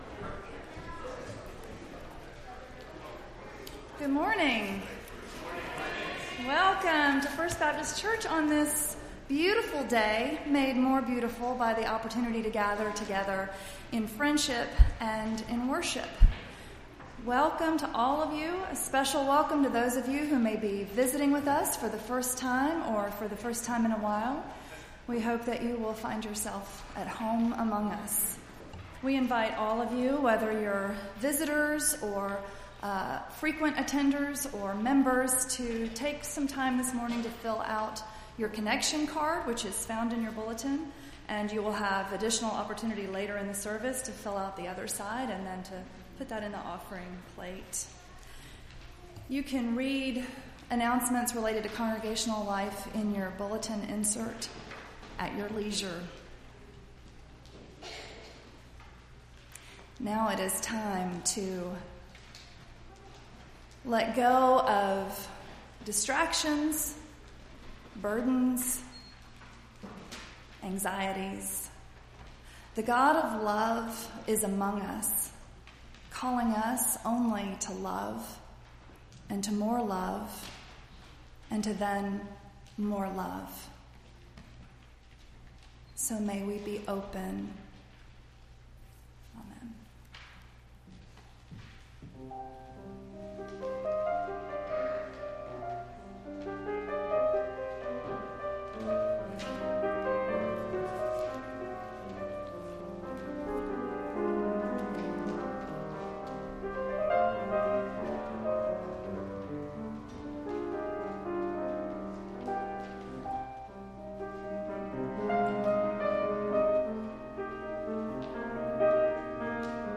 Entire February 19th Service